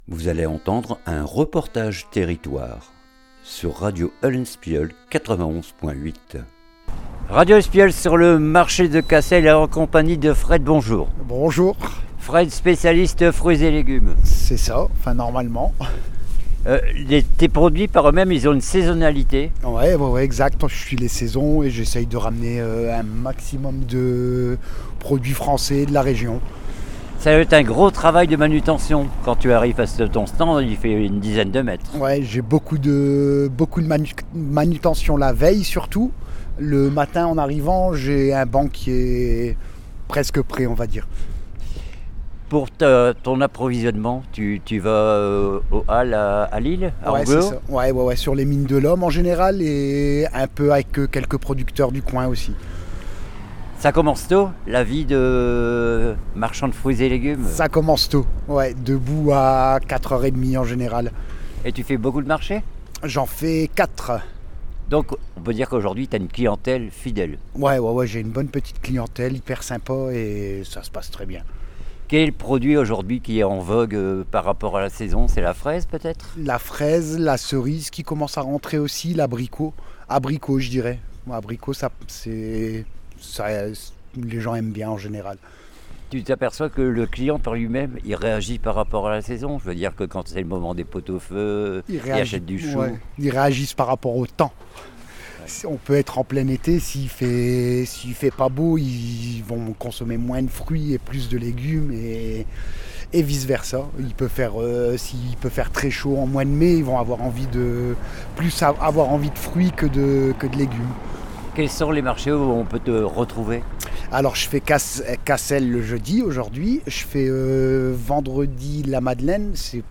REPORTAGE TERRITOIRE LE MARCHE DE CASSEL